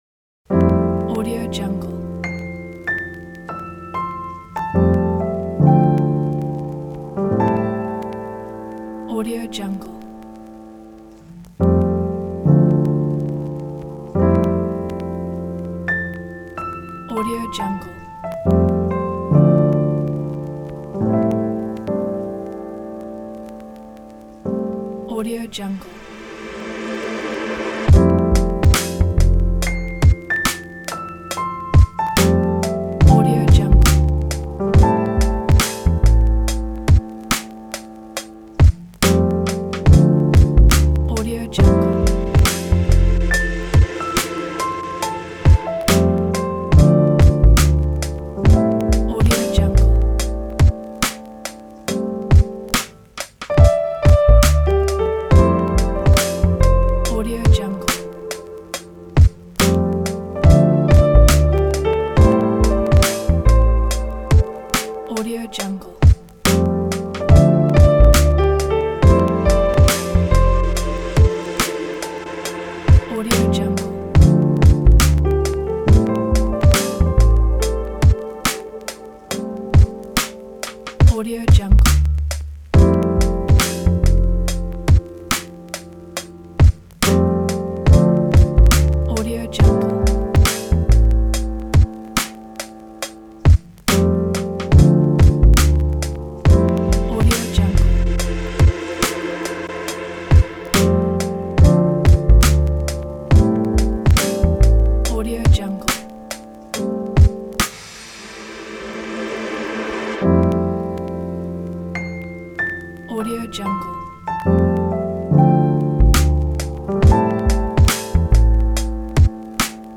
آهنگ صوتی آهسته، آرام و آسان
آمبیانس و آرام